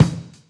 • Kick One Shot E Key 188.wav
Royality free kick drum single shot tuned to the E note. Loudest frequency: 479Hz
kick-one-shot-e-key-188-PLA.wav